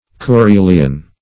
Koorilian \Koo*ril"i*an\, a & n.